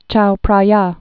(chou prä-yä)